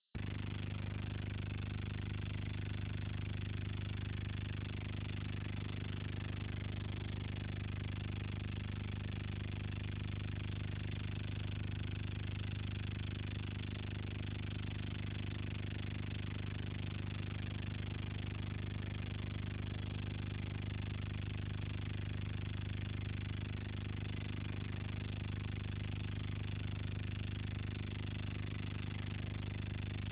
• ▲ ▼ Ich besitze den Kopfhörer "sms audio biosport" der den Herzpuls am Ohr erfasst und über die Audiobuchse auf ein androidgerät überträgt, wo es von der APP "runkeeper" ausgewertet wird.
recordingSMS-Audio-Bio.wav